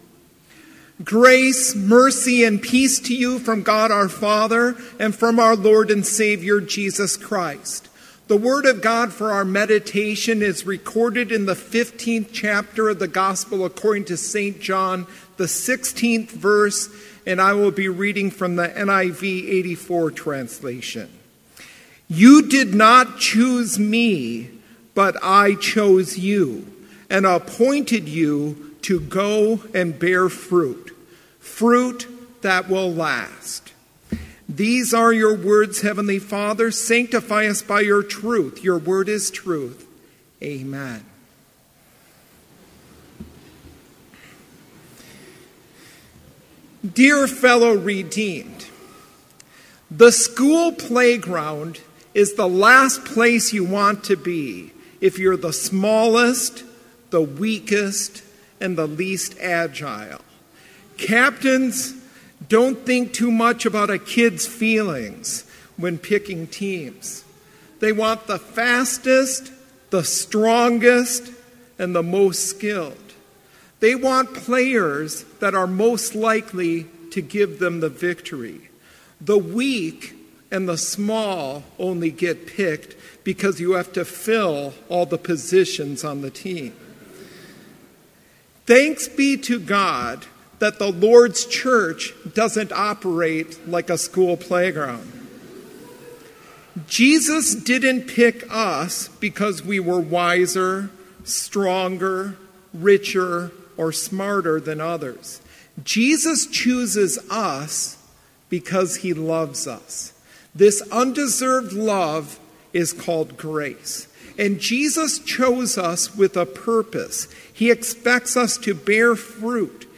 Complete Service
• Prelude
• Devotion
This Chapel Service was held in Trinity Chapel at Bethany Lutheran College on Thursday, September 13, 2018, at 10 a.m. Page and hymn numbers are from the Evangelical Lutheran Hymnary.